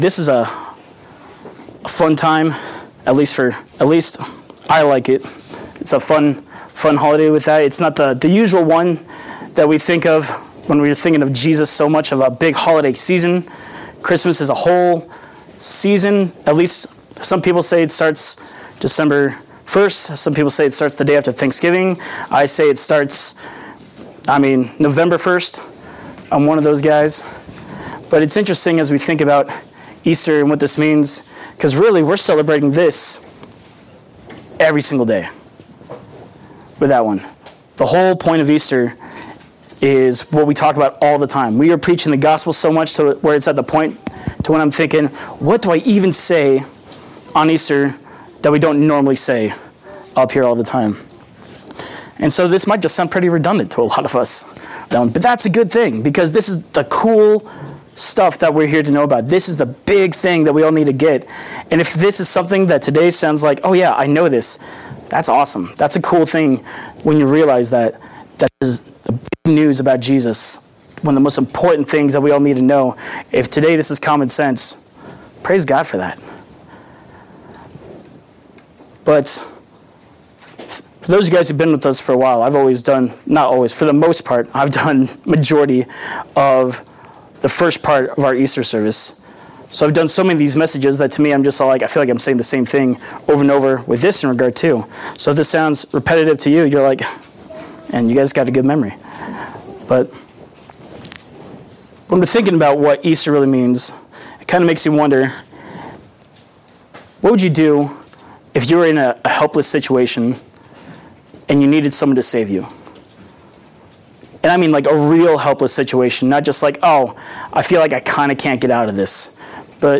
Easter Son-Rise Service 2024